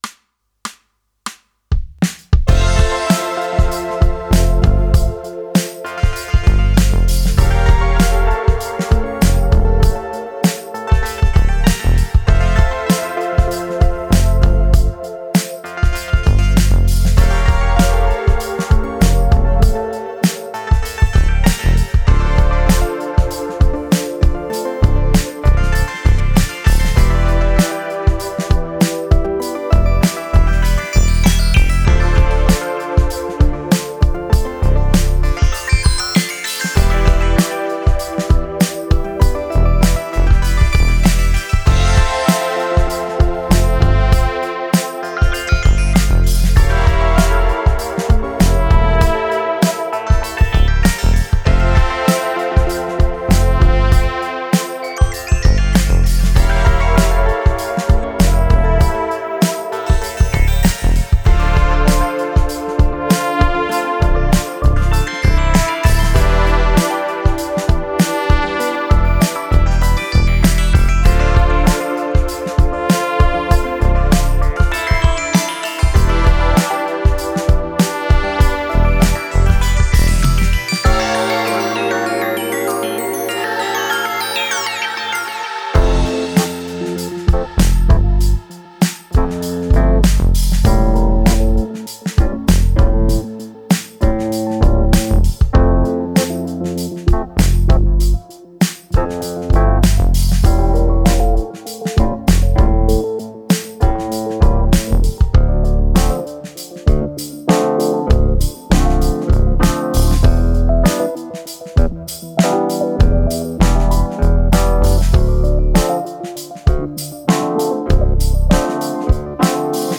HACEDOR DE RITMOS Y PINCHA DISCOS